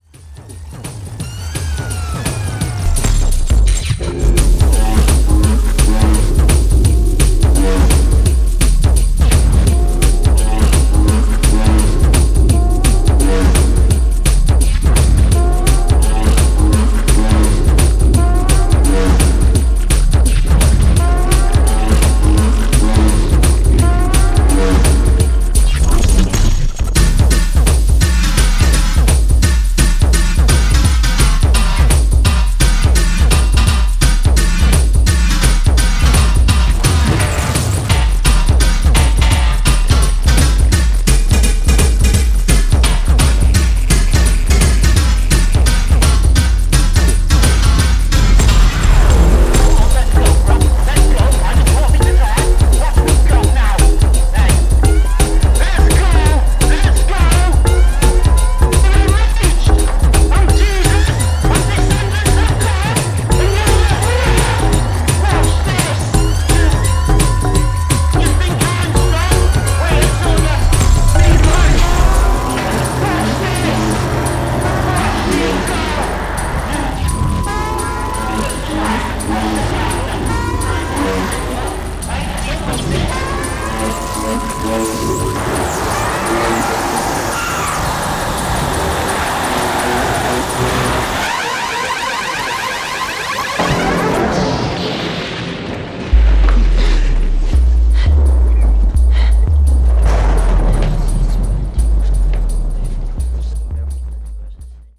ブルンディドラムを取り入れたDNB的な、トライバル縦ノリ怒涛のドラムチューン